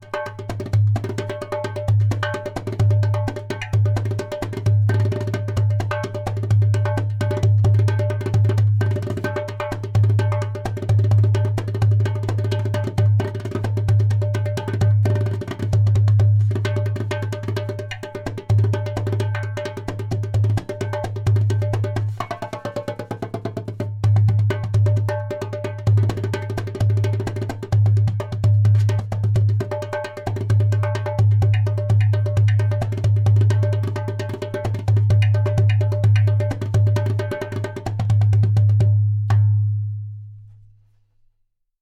130 bpm:
Sharp, deep sound with the raw beauty of the desert.
• Taks with harmonious overtones.
• Deep bass for a solo darbuka.
• Loud clay kik/click sound for a solo darbuka!